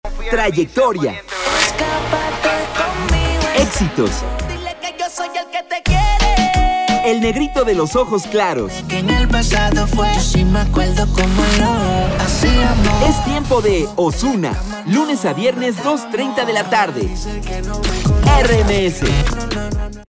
Male
Eloquent
Friendly
Energetic
Warm
Radio / TV Imaging
Words that describe my voice are Eloquent, Warm, Friendly.
0225RMS_PROMO.mp3